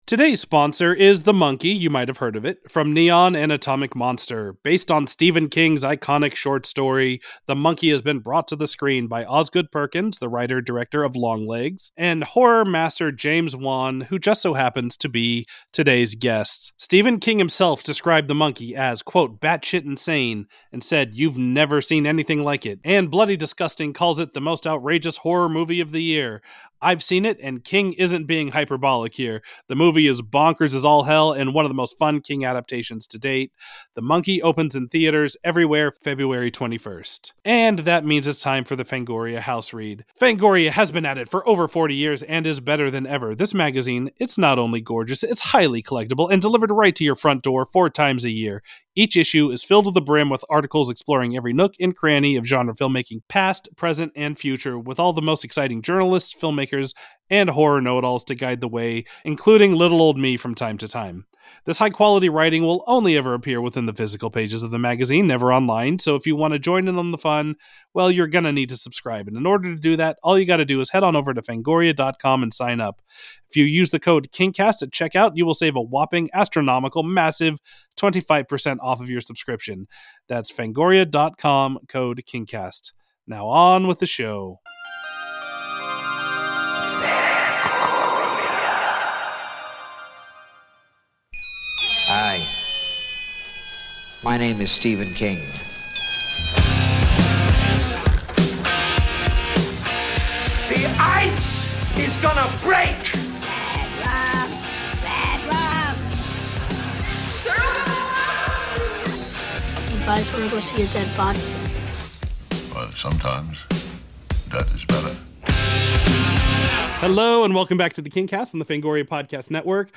An Interview with James Wan